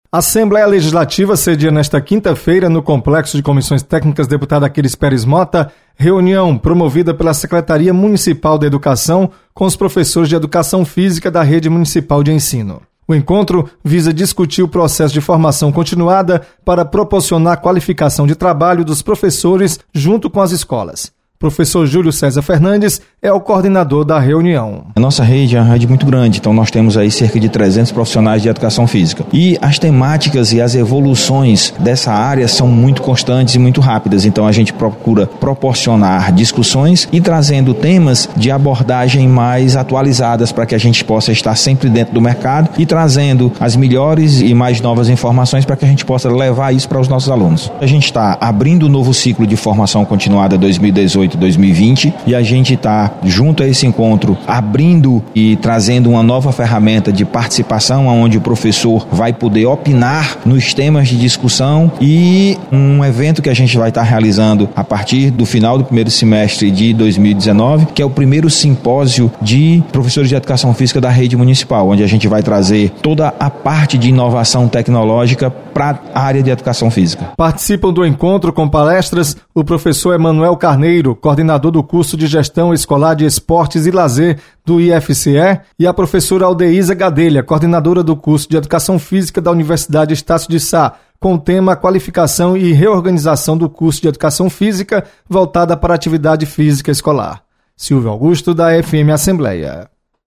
Assembleia sedia reunião sobre Gestão Escolar. Repórter